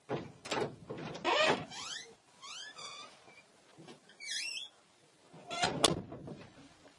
SOUND EFFECTS " 门吱吱声
描述：一个简单的家庭房间门被嘈杂地打开和关闭的录音
Tag: 吱吱 踩住 摇摇欲坠 squeek